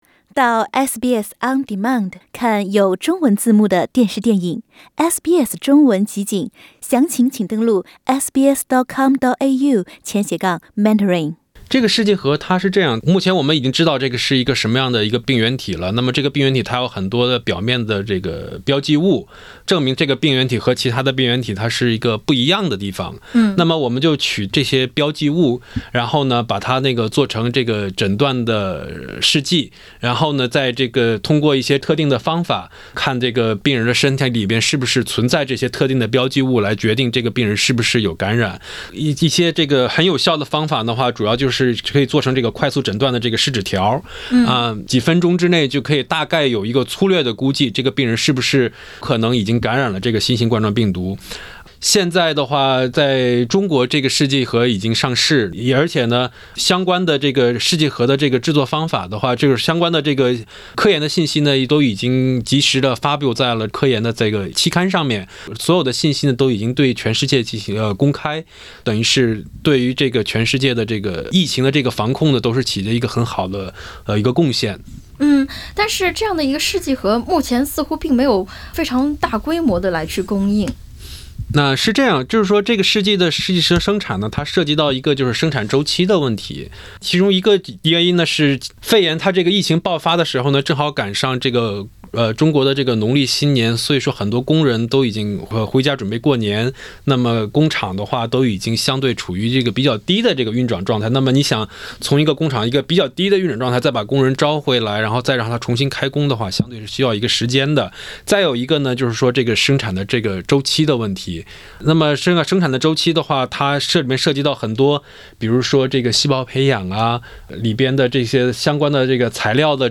传染病专家专访：冠状病毒是“生化武器”？假的